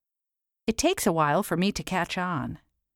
Bem, é sobre ele que falaremos no post de hoje, com vários exemplos e áudios gravados por nativos.